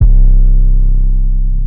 808s
No Advance 808.wav